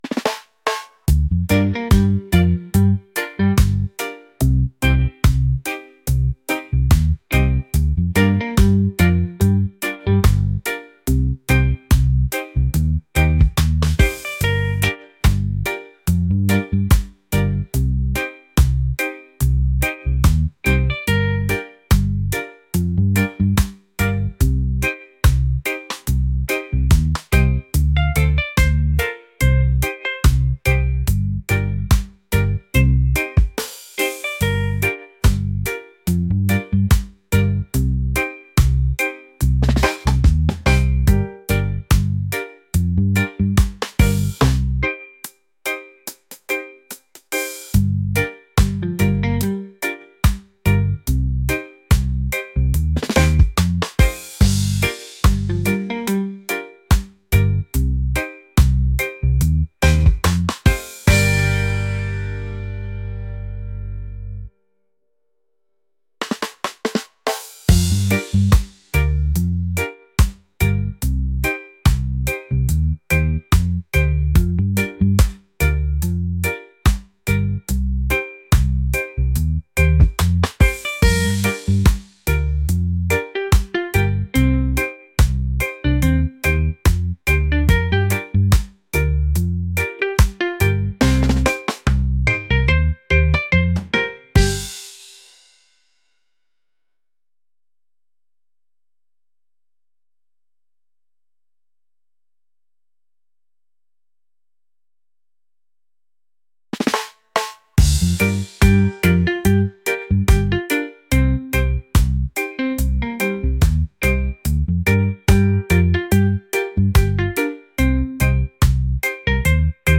groovy | laid-back | reggae